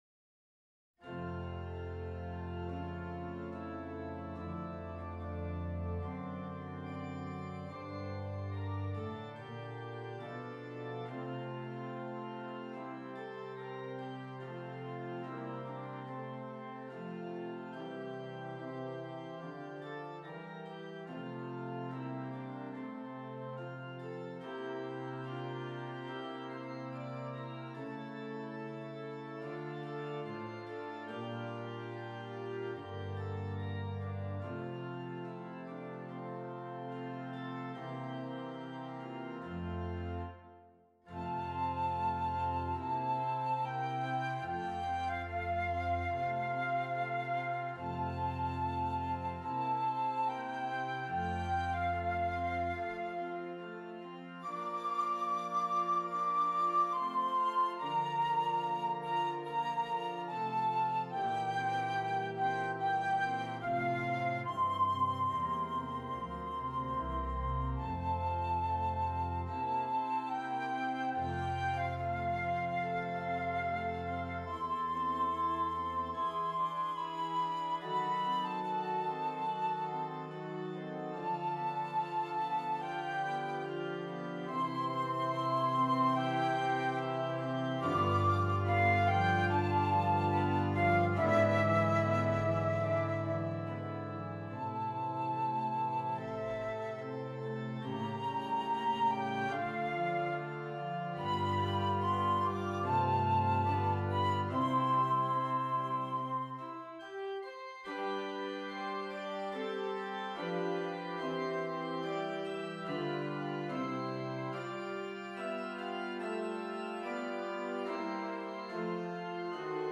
Flute and Keyboard